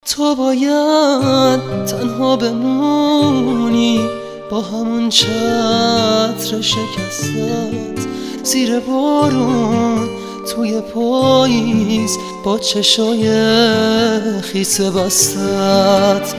آهنگ موبایل با کلام، زیبا و رمانتیک